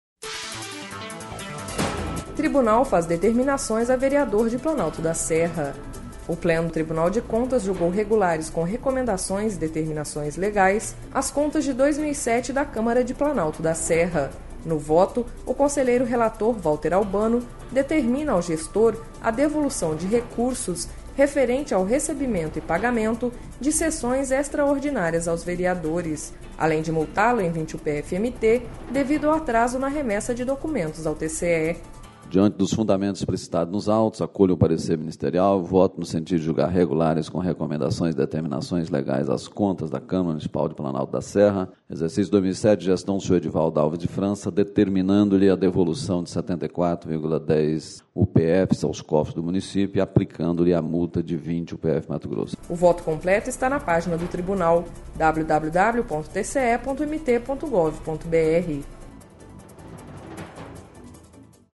Sonora: Valter Albano – conselheiro TCE-MT